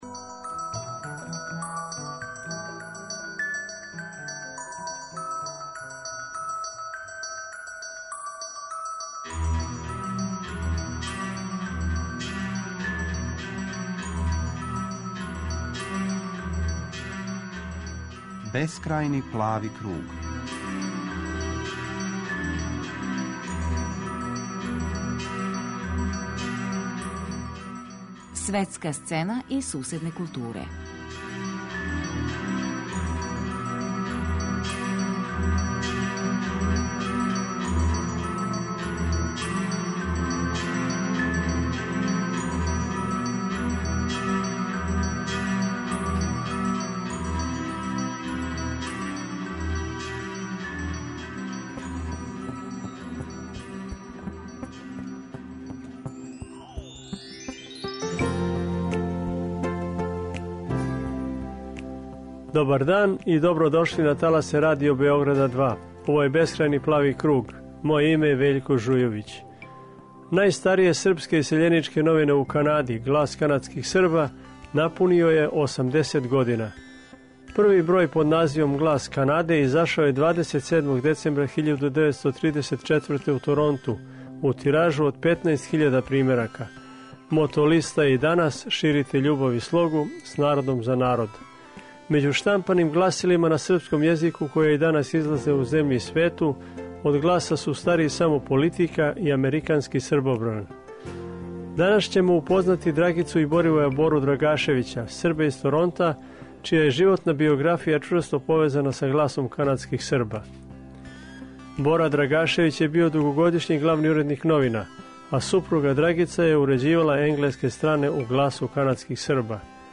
На крају емисије, чућемо репортажу